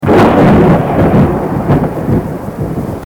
Thunder
Thunder.mp3